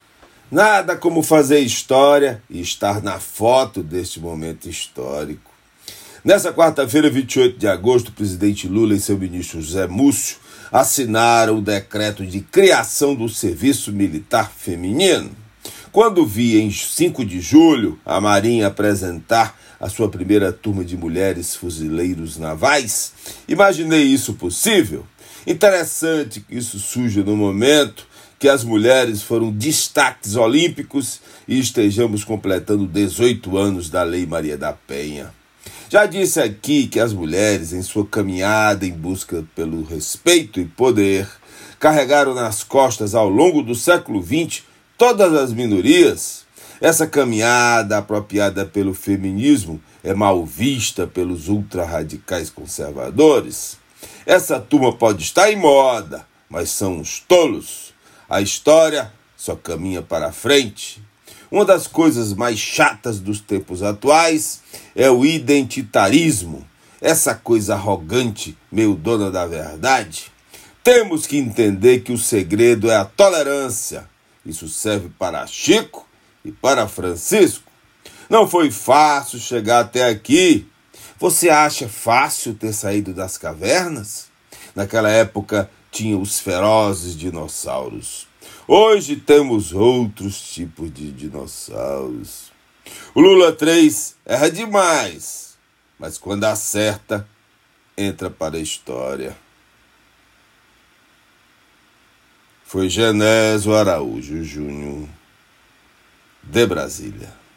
Comentário desta quinta-feira
direto de Brasília.